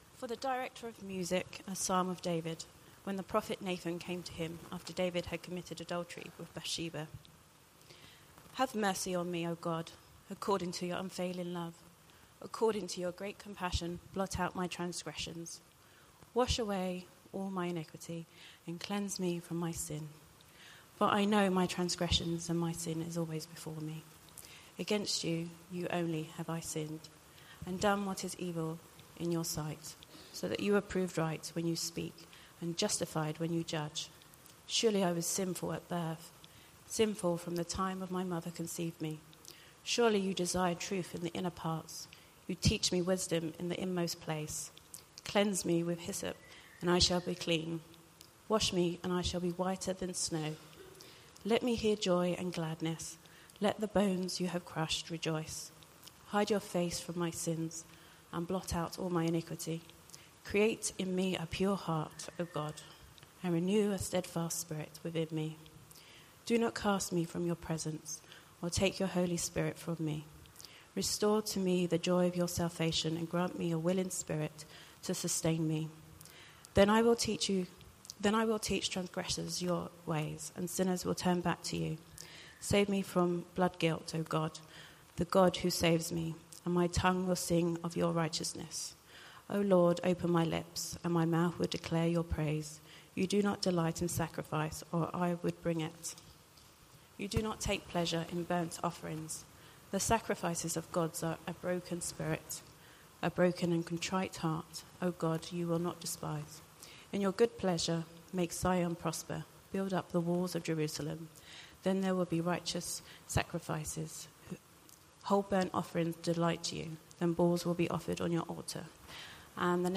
Talk 2 of 3 in the Series: Parables With Bite
Dundonald Church, London UK Talk 2: “Inside-Out” Religion